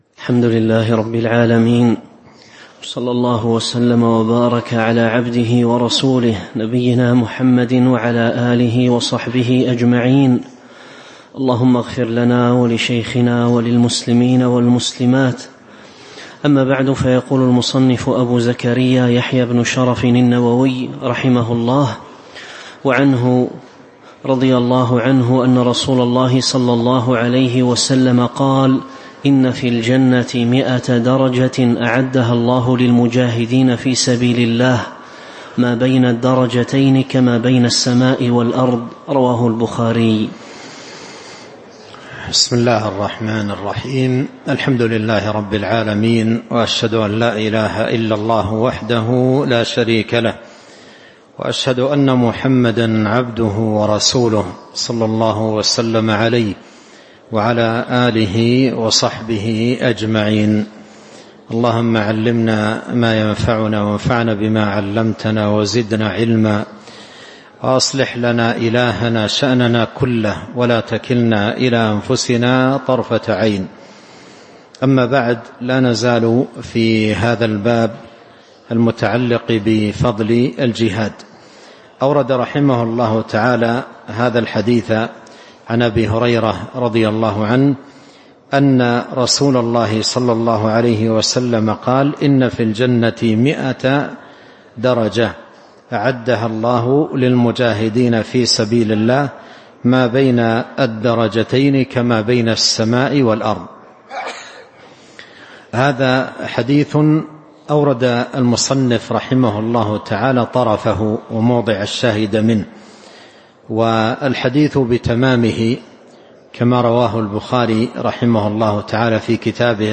تاريخ النشر ٣ رجب ١٤٤٥ هـ المكان: المسجد النبوي الشيخ